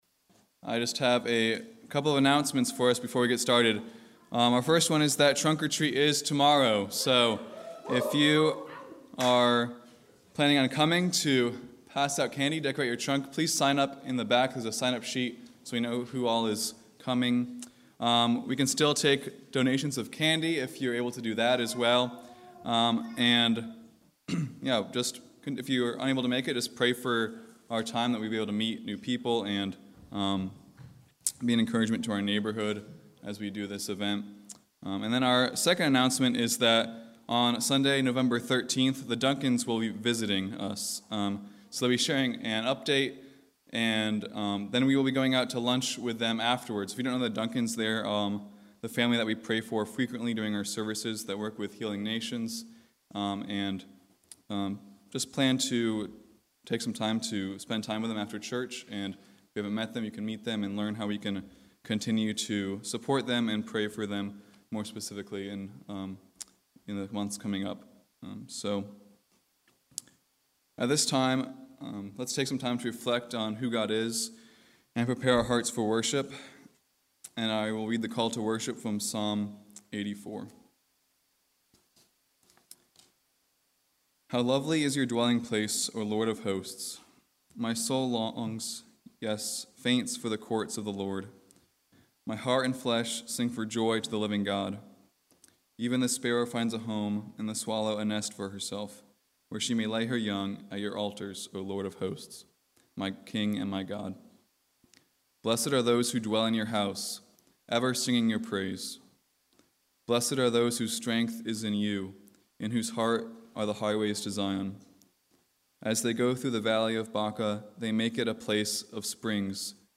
October 30 Worship Audio – Full Service